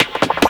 FILLSNARE1-R.wav